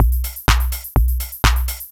Sub Beat_125.wav